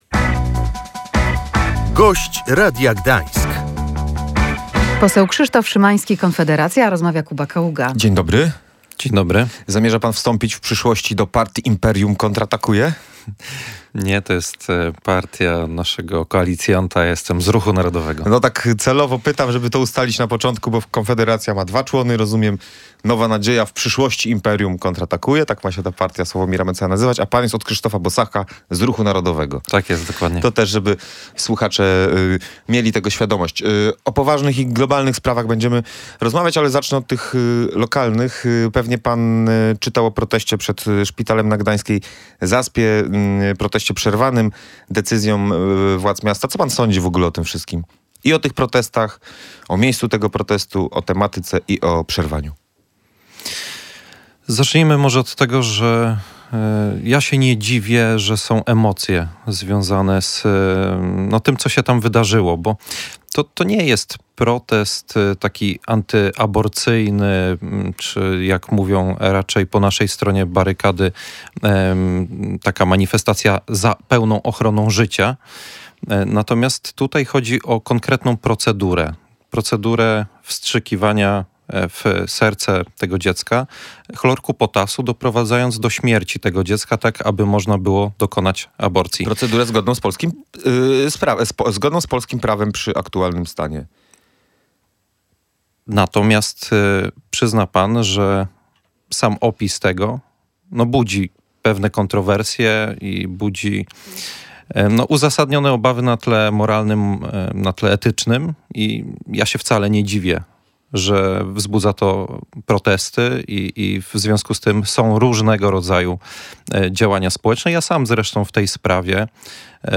Gość Radia Gdańsk podkreślał, że łowiectwo jest ważną gałęzią gospodarki.